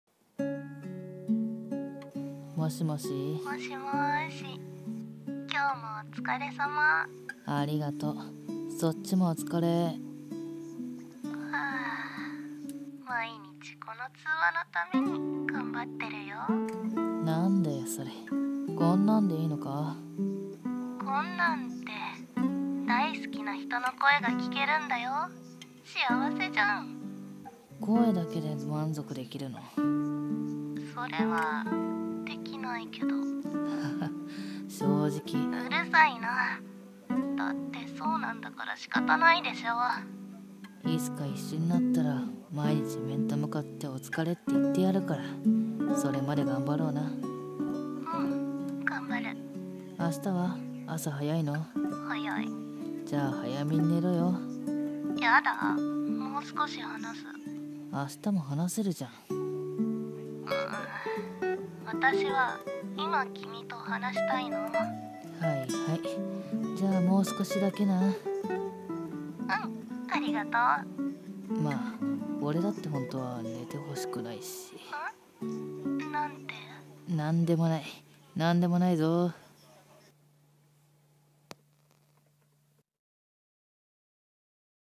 【声劇台本】寝る前に聴く、君の声【掛け合い】 コラボ